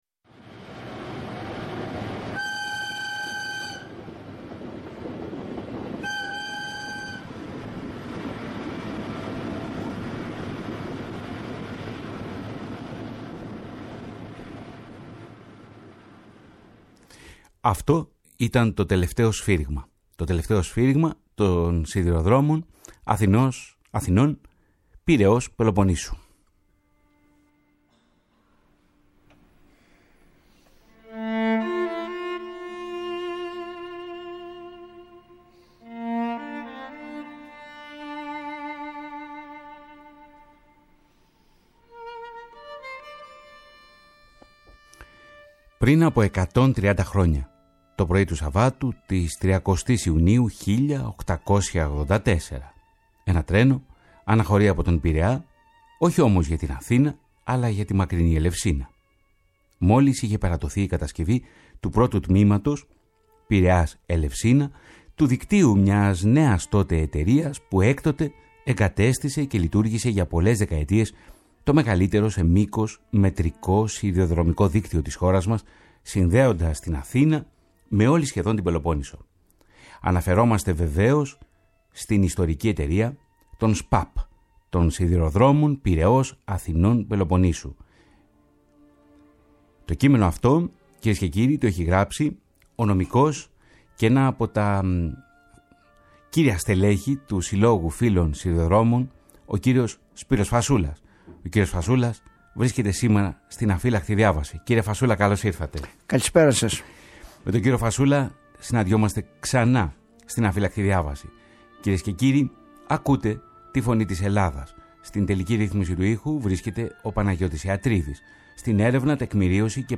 Η “Αφύλαχτη Διάβαση” ξετυλίγει την ιστορία των των Σιδηροδρόμων Πειραιώς- Αθηνών- Πελοποννήσου» («Σ.Π.Α.Π.») μέσα από την αφήγηση ενός κορυφαίου παλαίμαχου σιδηροδρομικού